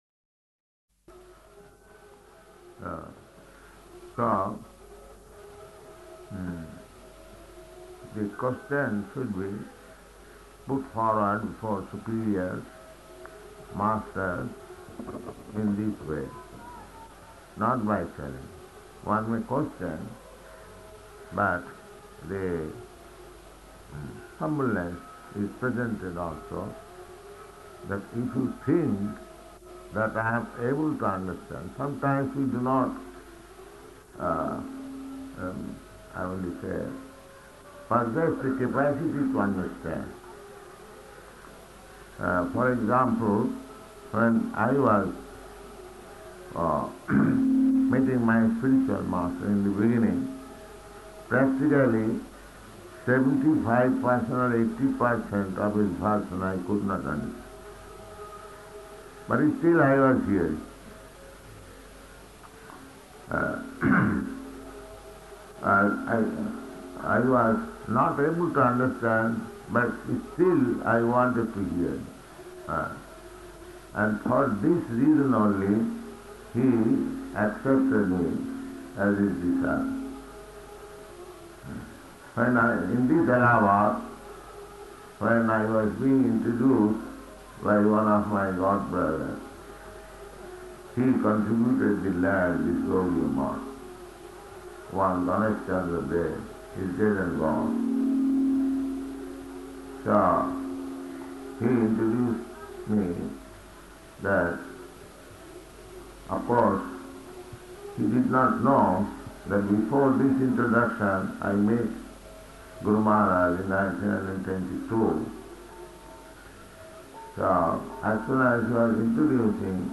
Location: Allahabad